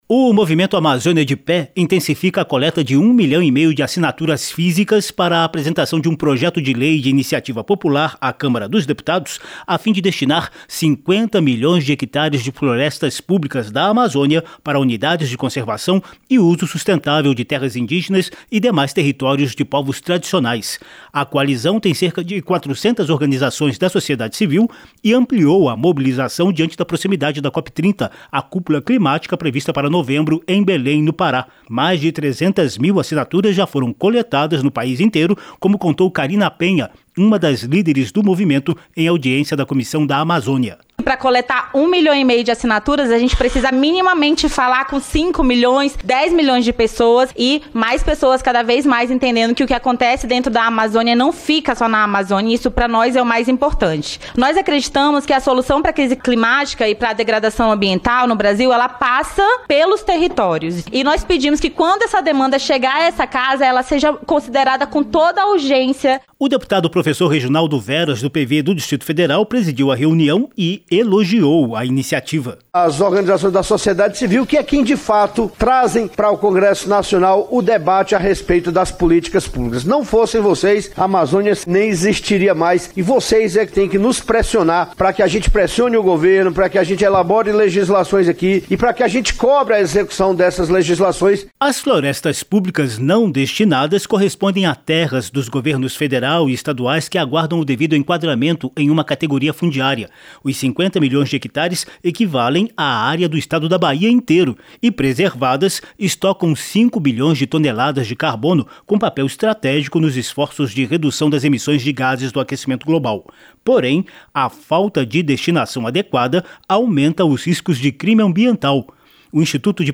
PERTO DA COP-30, CRESCE A MOBILIZAÇÃO DA SOCIEDADE CIVIL PARA DESTINAÇÃO DE FLORESTAS PÚBLICAS NA AMAZÔNIA. MAIS DETALHES COM O REPÓRTER